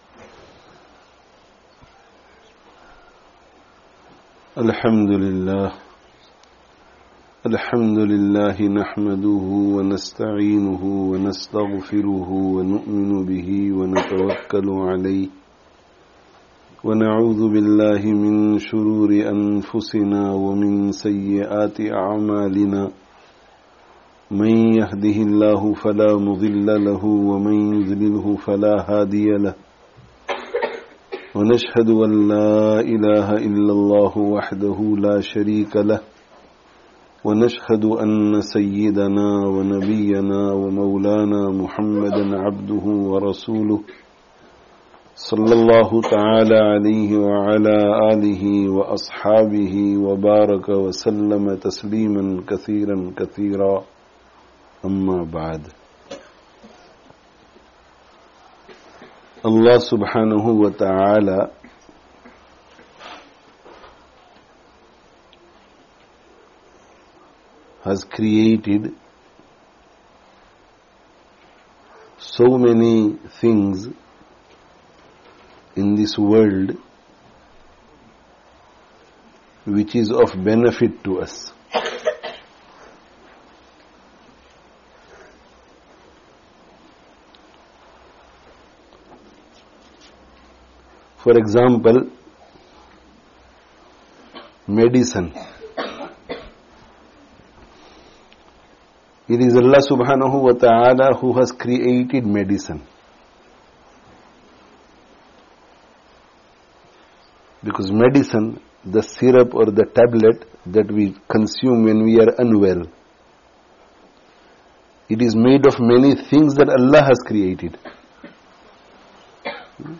'Ilm and 'Amal - A Great Blessing [Annual Jalsah] (Al-Madrasatul Madaniyah, St. Matthews, Leicester 31/07/19)